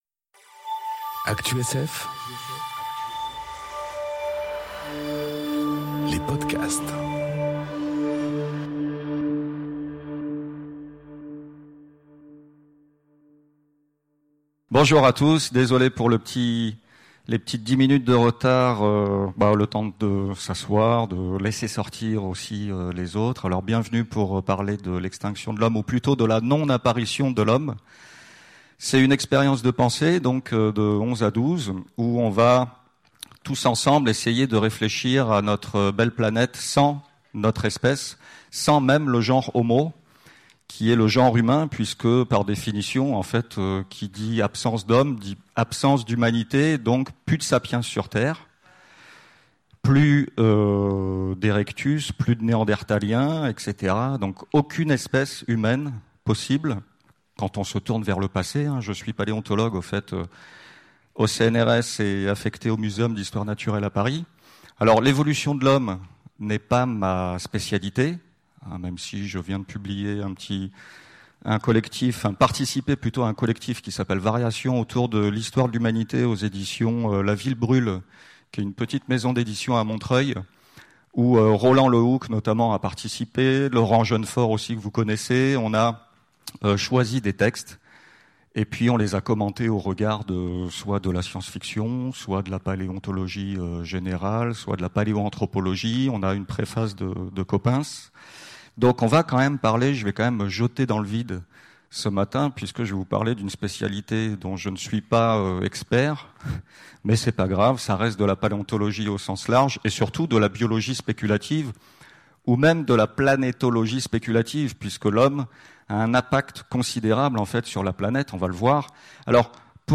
Conférence Expérience de pensée : Aujourd’hui, sans nous ? enregistrée aux Utopiales 2018